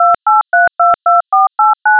I take it the recording is just you keying in DTMF tones in which case the wav file works but the wavex doesn’t. Thanks for all you help.